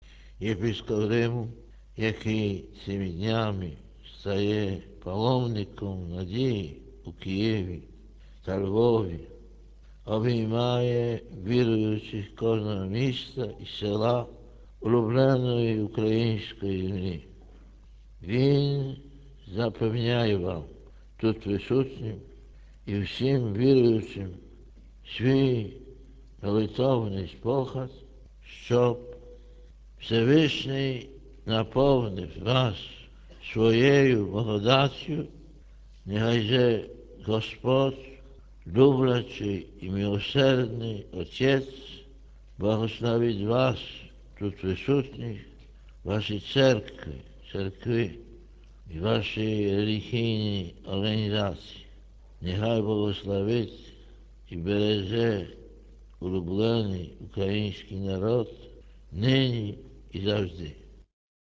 Згадаймо сьогодні його голос і молитву за народ України, записані у Києві 24 червня 2001 року: